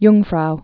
(yngfrou)